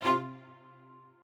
strings6_13.ogg